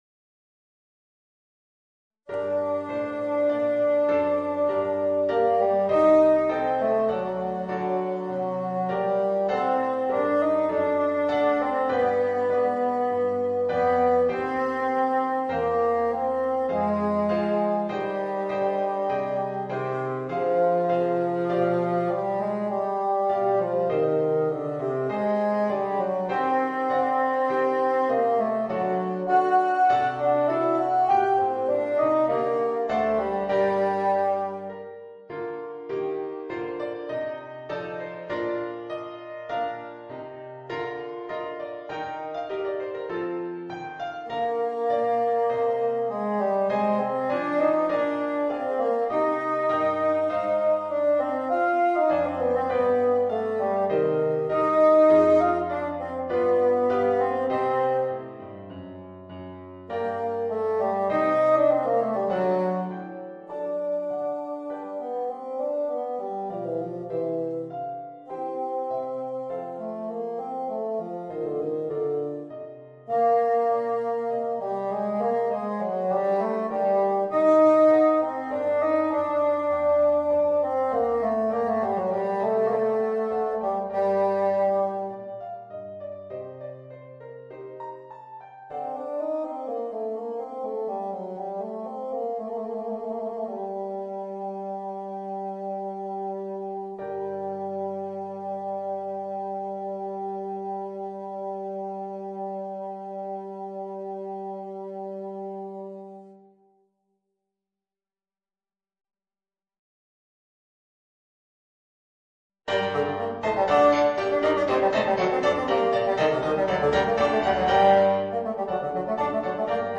Voicing: Bassoon and Organ